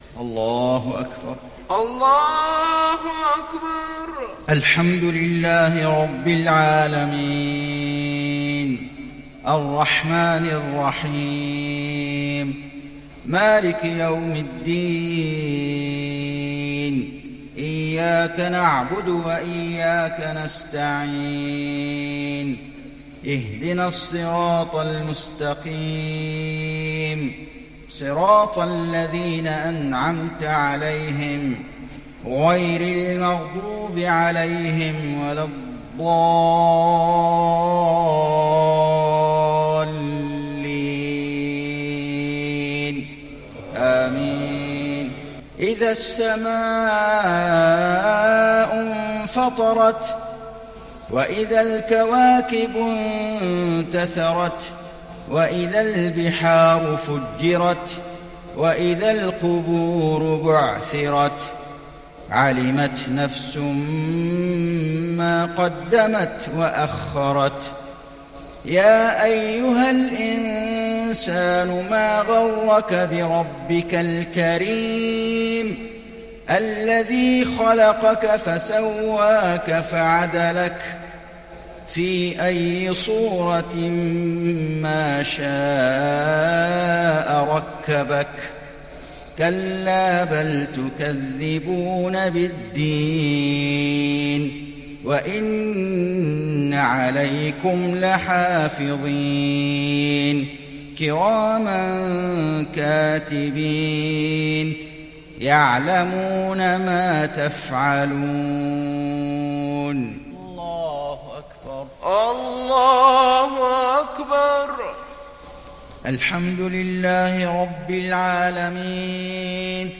صلاة المغرب للشيخ فيصل غزاوي 8 جمادي الآخر 1441 هـ
تِلَاوَات الْحَرَمَيْن .